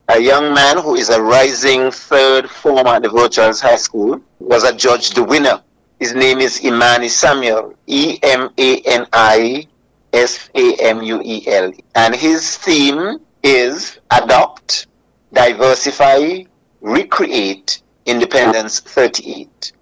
That was Permanent Secretary in the Ministry of Education (St. Kitts), Mr. William Vincent Hodge.